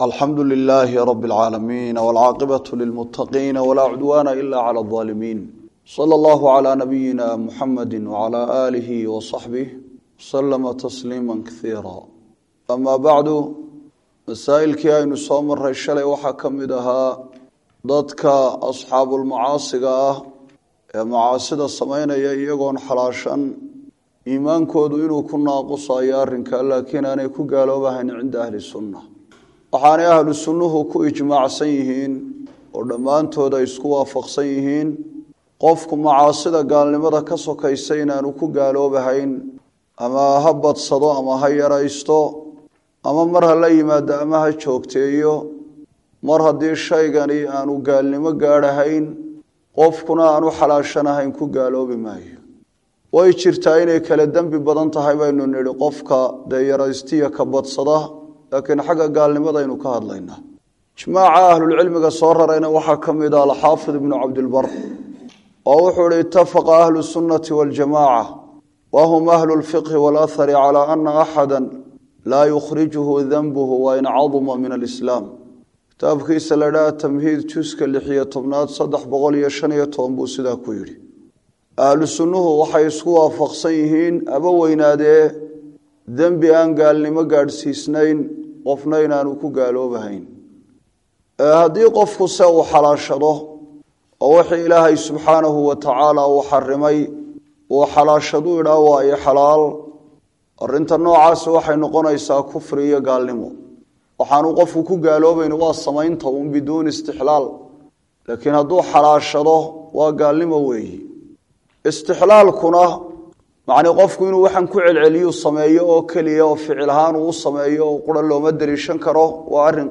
Sharaxa Kitaabka Ictiqaadka Aimada Xadiithka - Darsiga 8aad - Manhaj Online |